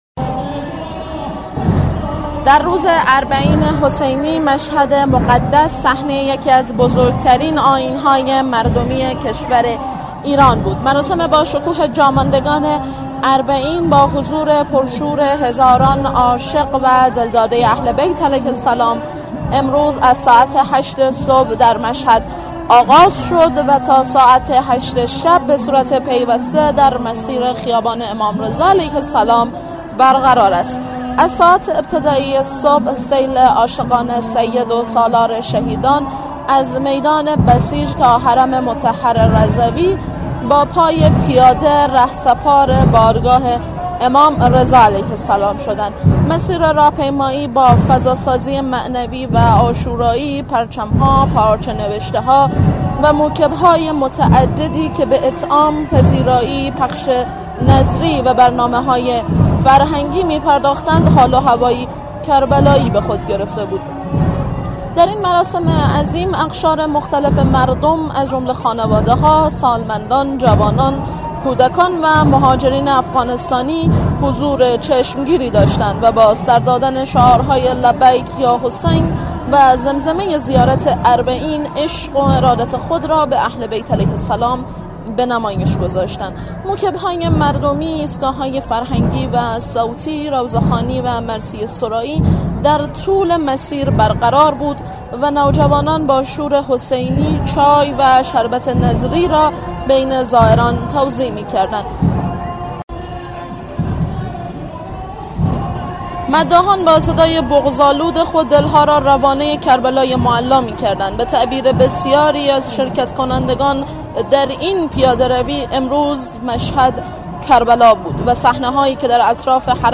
مداحان با صدای بغض‌آلود خود دل‌ها را روانه کربلای معلی می‌کردند.